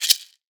West MetroPerc (42).wav